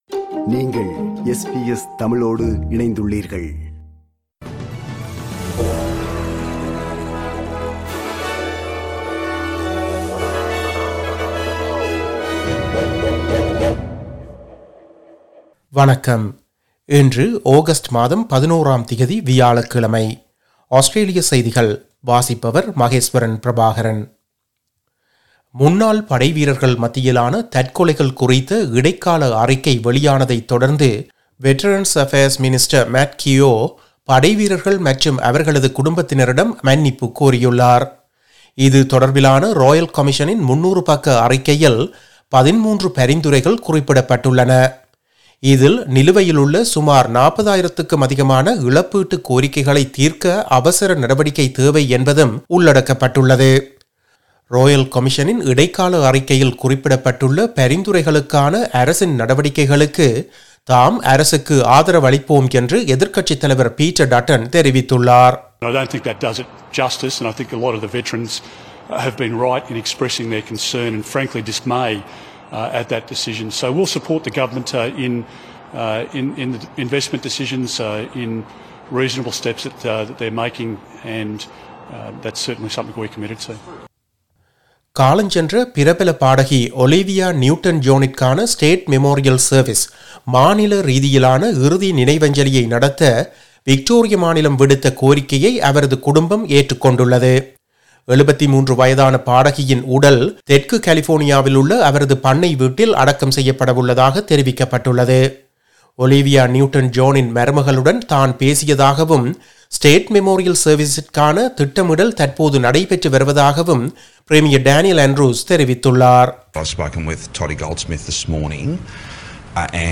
Australian news bulletin for Thursday 11 August 2022.